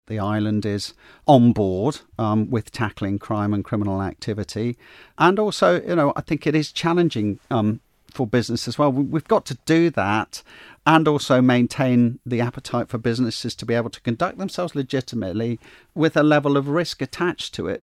Alfred Cannan says it's a difficult balancing act ensuring the Island's sectors are compliant while remaining competitive: Listen to this audio